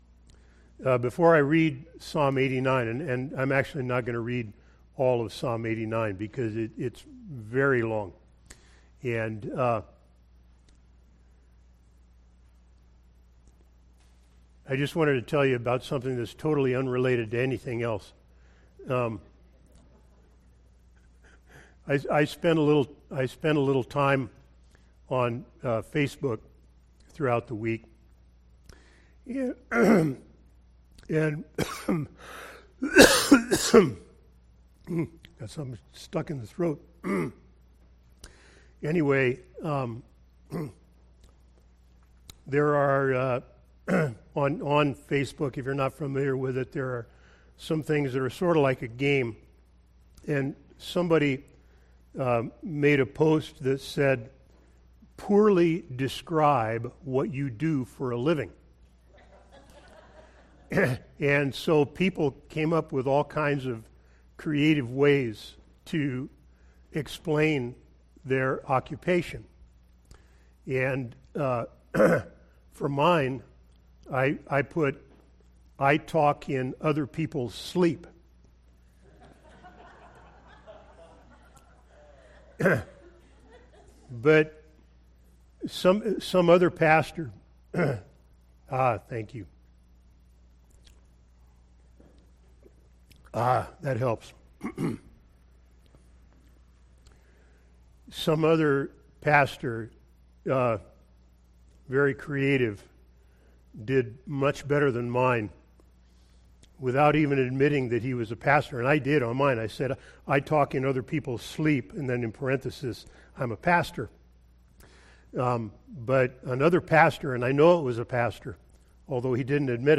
Sermon text: Psalm 89 Sermon: “Long Promised King”